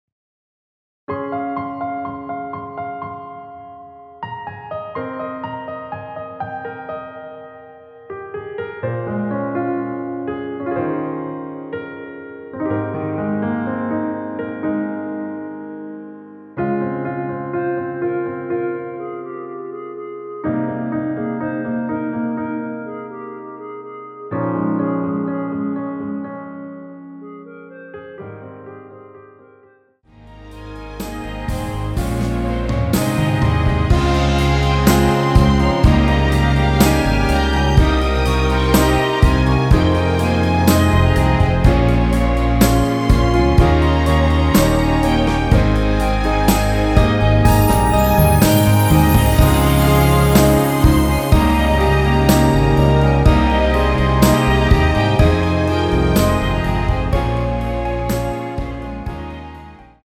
원키 멜로디 포함된 MR입니다.(미리듣기 확인)
Ab
앞부분30초, 뒷부분30초씩 편집해서 올려 드리고 있습니다.
중간에 음이 끈어지고 다시 나오는 이유는